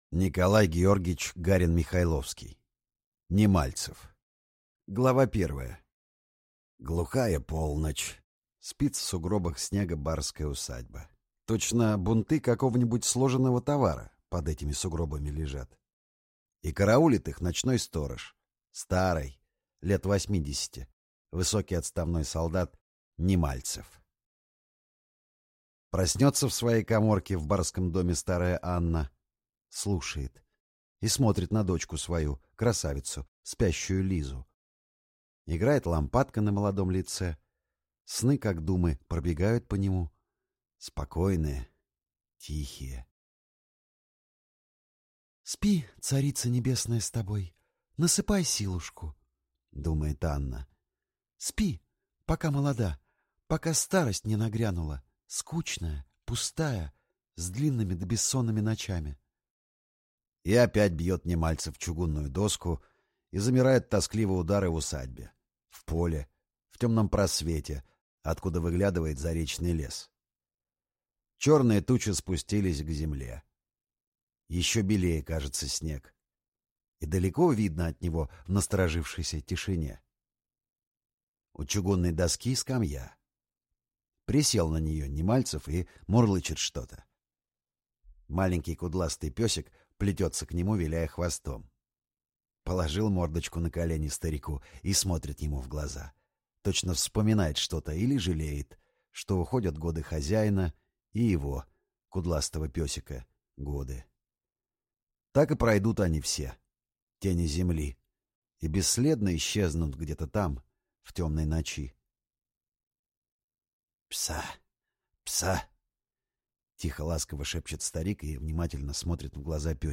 Аудиокнига Немальцев | Библиотека аудиокниг